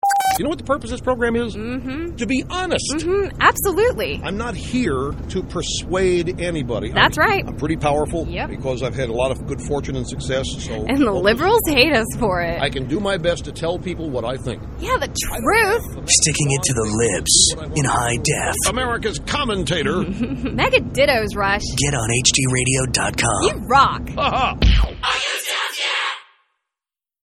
A former colleague now working at a major-market station sent along a CD containing some of the Clear Channel-produced spots now airing on stations nationwide promoting the arrival of digital radio.